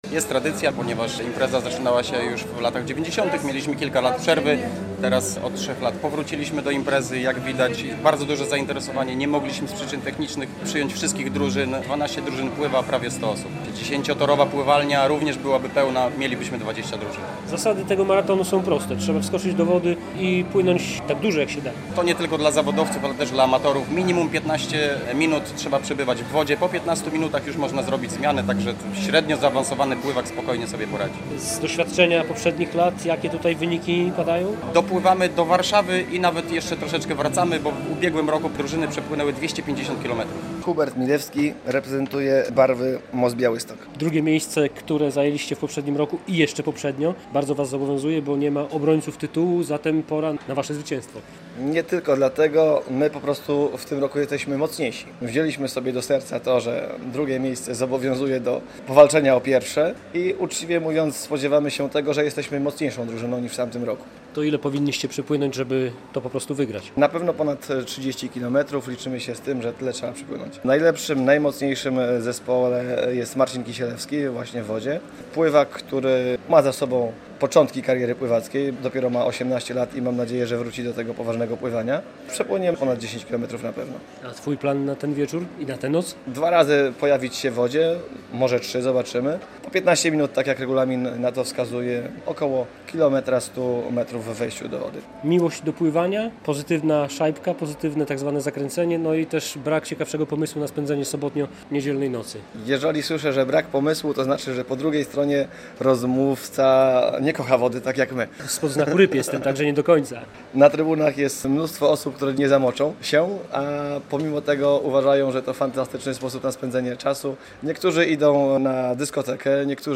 Gwiazdkowy Maraton Pływacki - relacja